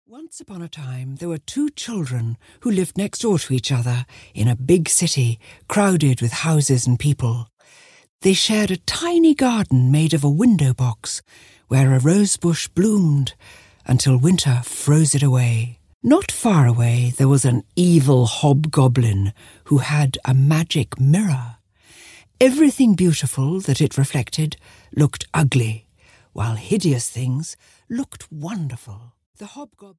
The Snow Queen (EN) audiokniha
Joanna Lumley reads "The Snow Queen".
Ukázka z knihy